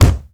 punch_low_deep_impact_08.wav